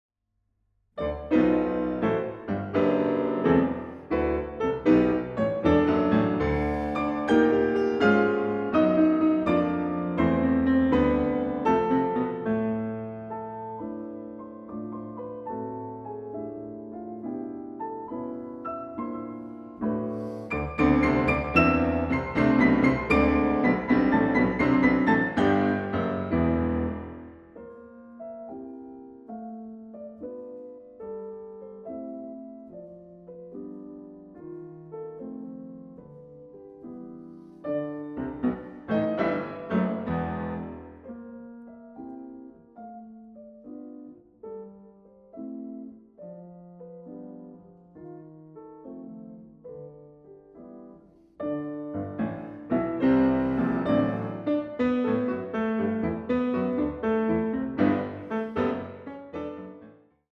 A piano journey through various European musical worlds
Piano